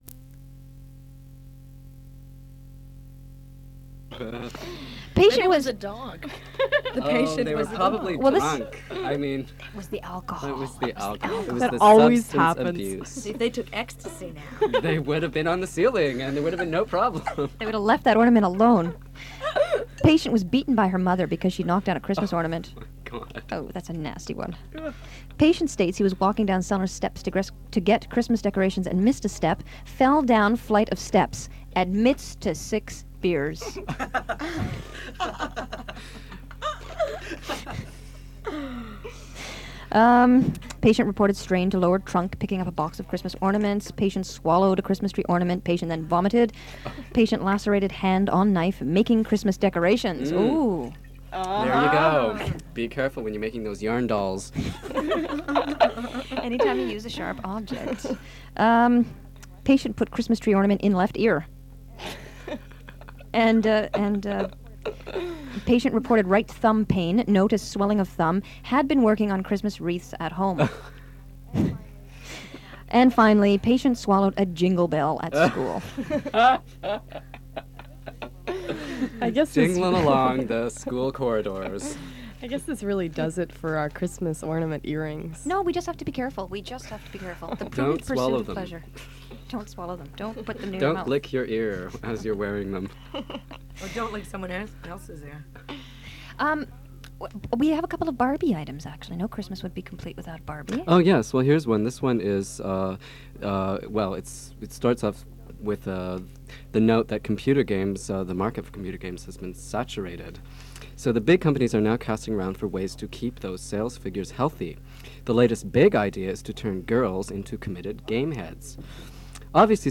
They discuss Christmas mishaps, Barbie, the Lesbian Show on Co-Op radio in Vancouver, Chanukah and Winter Solstice. Features a clip from a Sandra Bernhard Christmas Comedy Segment.